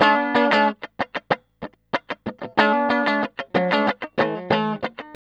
TELEDUAL D#3.wav